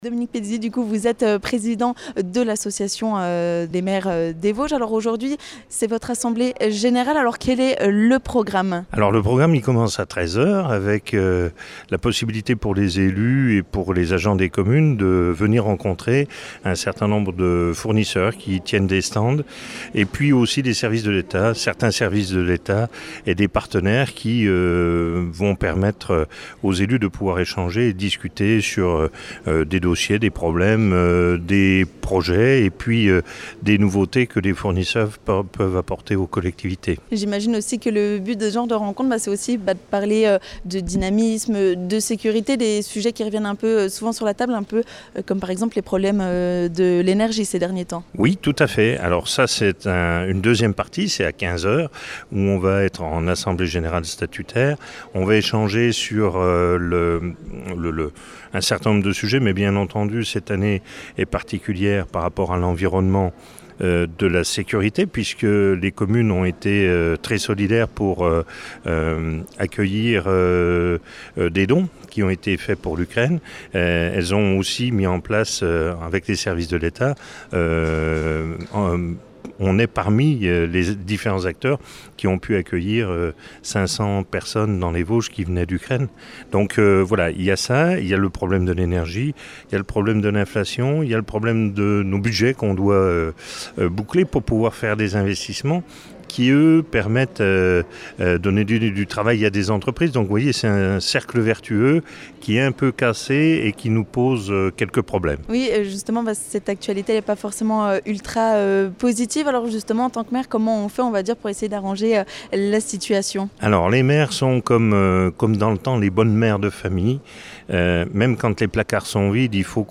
L'occasion pour nous de partir à la rencontre de son président, Dominique Peduzzi.
Ecoutez Dominique Peduzzi, président de l'AMV 88, sur Vosges FM !